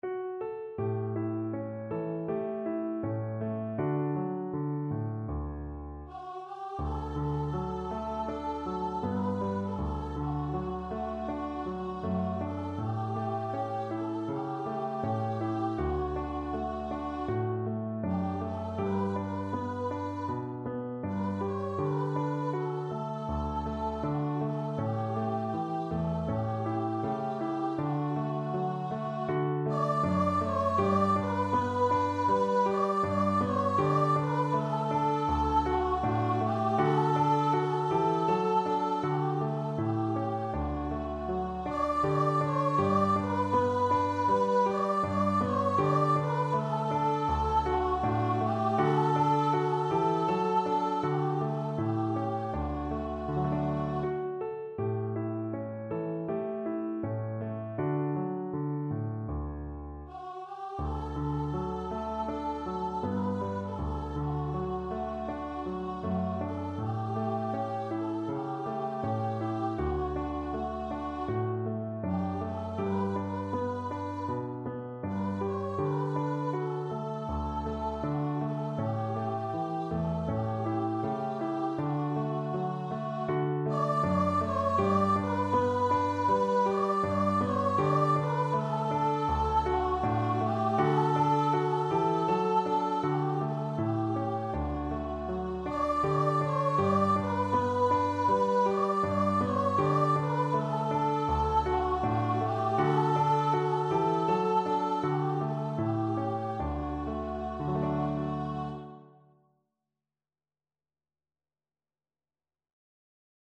Traditional Music of unknown author.
4/4 (View more 4/4 Music)
Andante
F#5-D6